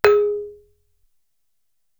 Index of /90_sSampleCDs/Best Service ProSamples vol.55 - Retro Sampler [AKAI] 1CD/Partition D/GAMELAN